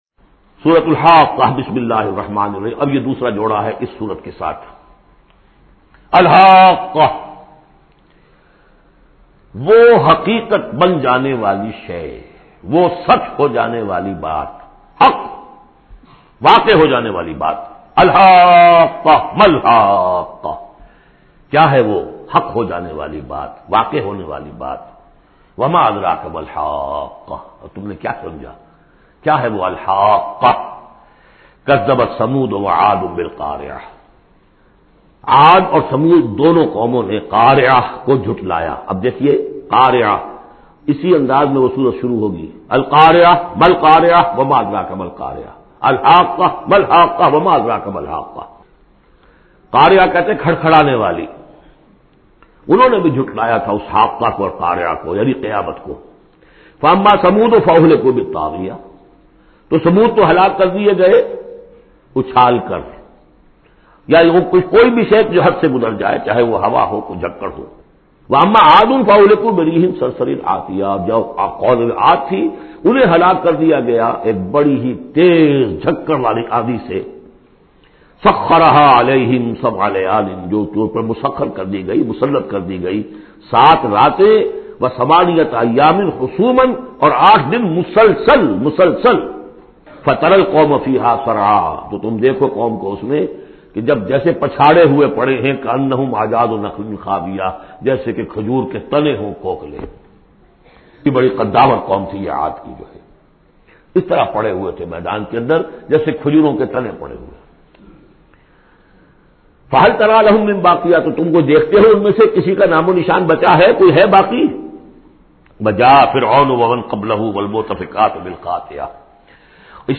Surah Haqqah Tafseer by Dr Israr Ahmed
Surah Haqqah, listen online mp3 urdu tafseer in the voice of Dr Israr Ahmed. Surah Haqqah is 69 chapter of Holy Quran.